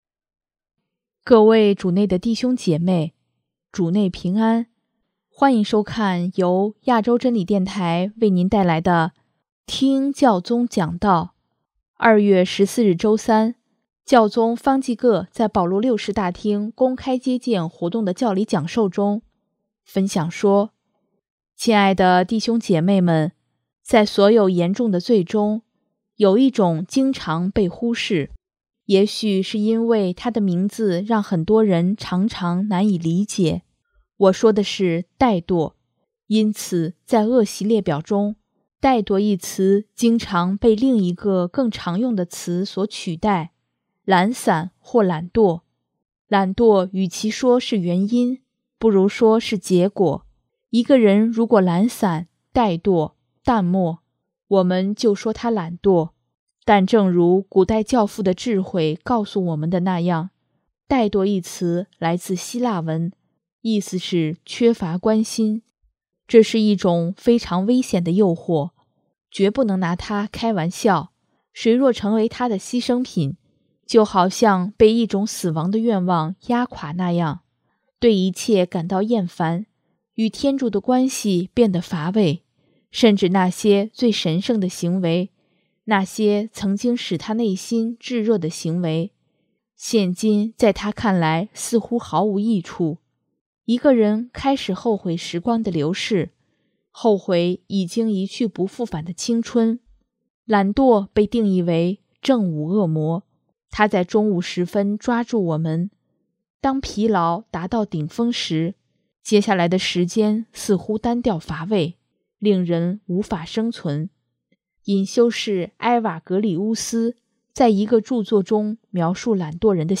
2月14日周三，教宗方济各在保禄六世大厅公开接见活动的教理讲授中，分享说：